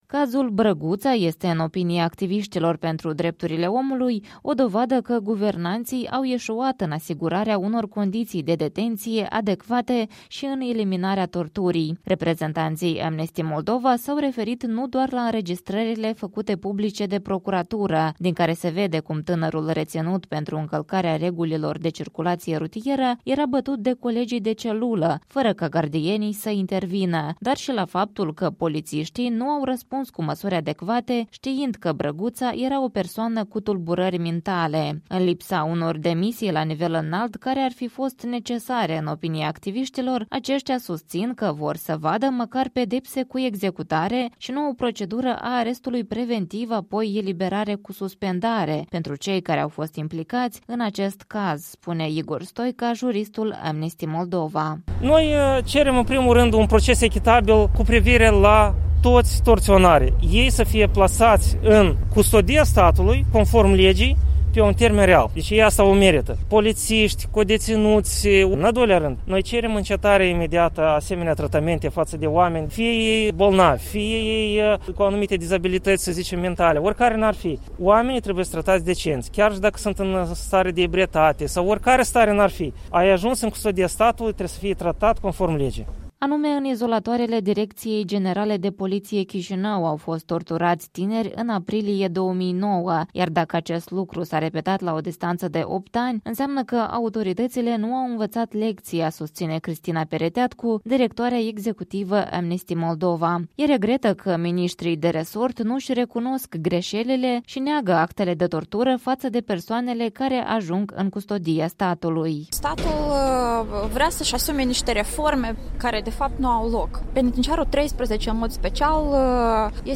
Protestul unui grup de activiști Amnesty International în fața Direcției Generale de Poliție la Chișinău.